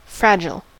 fragile: Wikimedia Commons US English Pronunciations
En-us-fragile.WAV